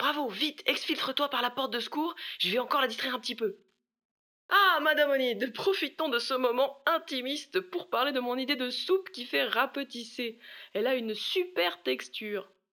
VO_LVL3_EVENT_Bravo reussite mission_02.ogg